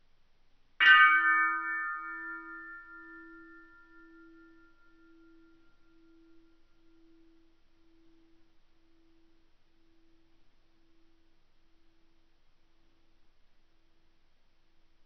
Bell 09
bell bing brass ding sound effect free sound royalty free Sound Effects